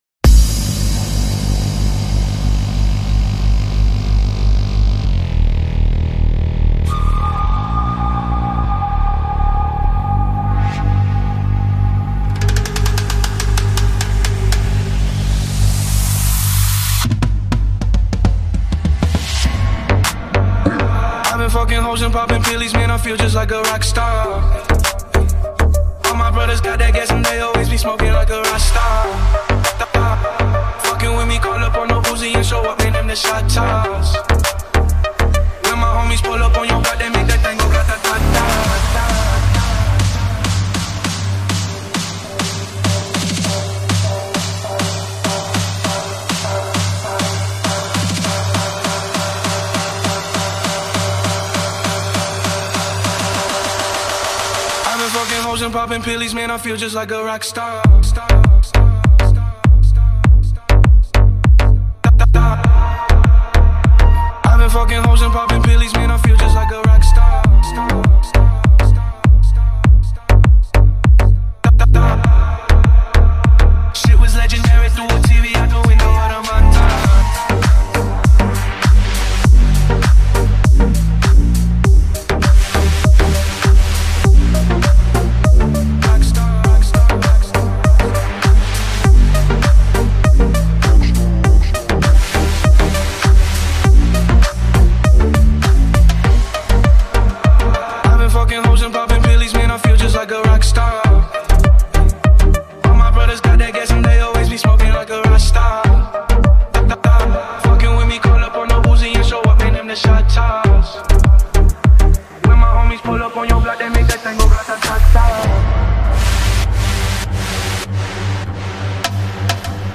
Bass Boosted